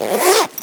action_open_backpack_4.ogg